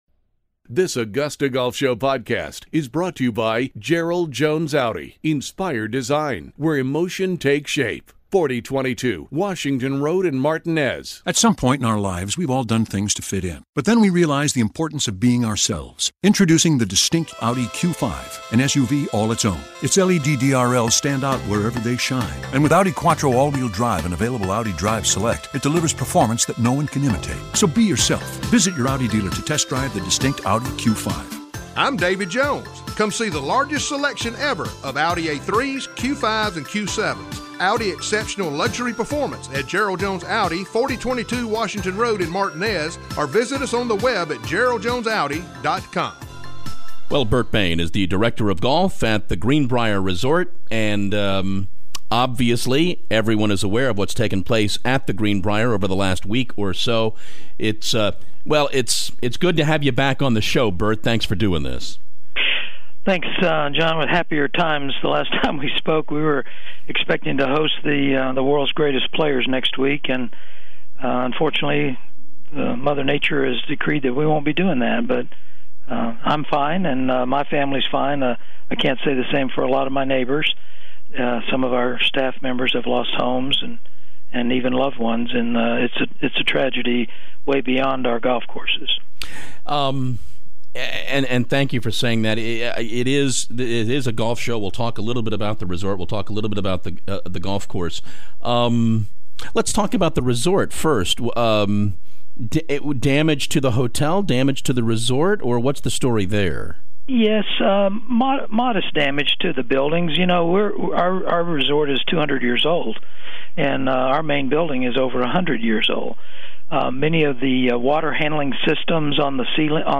The Greenbrier: The Augusta Golf Show Interview